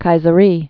(kīzə-rē, -sə-)